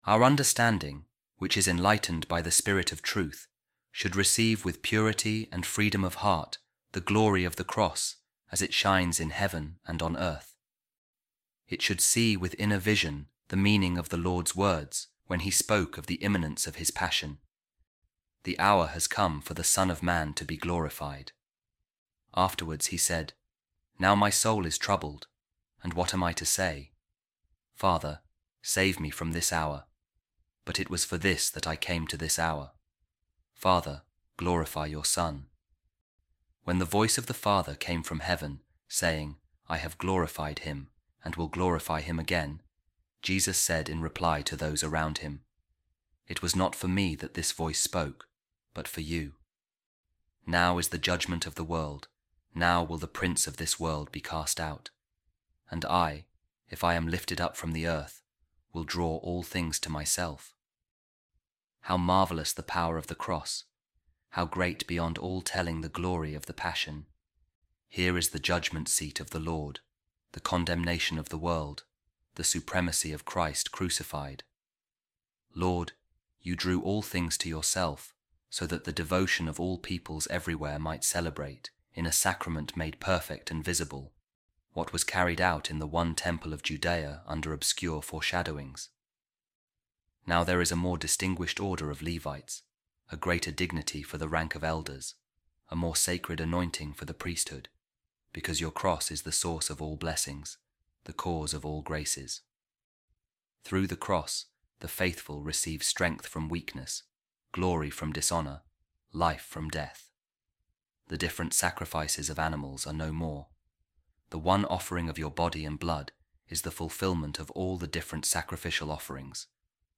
Office Of Readings | Tuesday, Lent Week 5 | A Reading From The Sermons Of Pope Saint Leo The Great